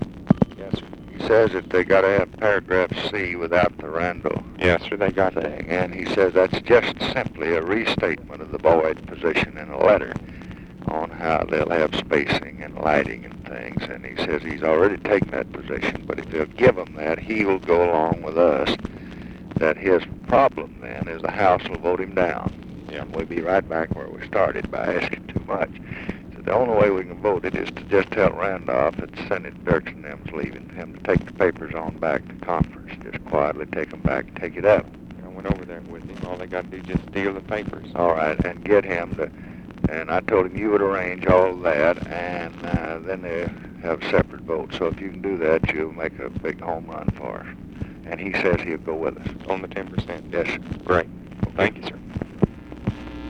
Conversation with BAREFOOT SANDERS, July 24, 1968
Secret White House Tapes